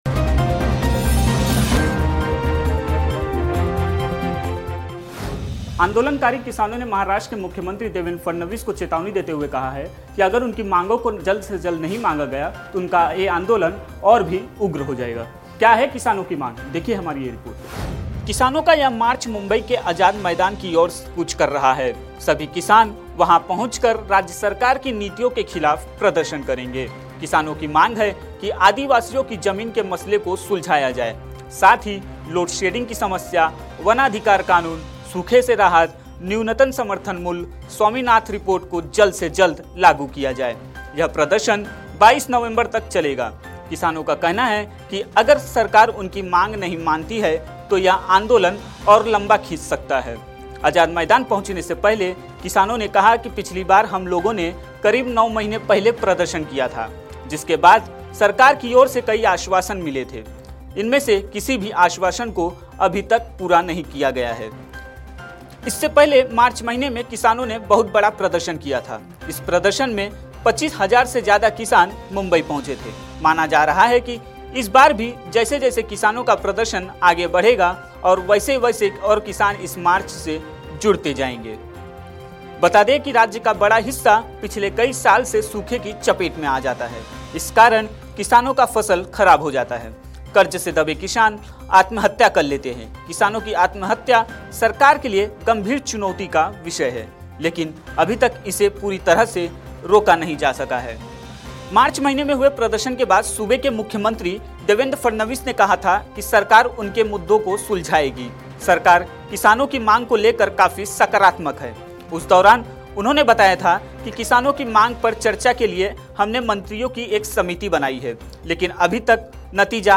न्यूज़ रिपोर्ट - News Report Hindi / देवेंद्र फडणवीस की चिंता बढ़ाने आए किसान, उग्र आंदोलन की धमकी !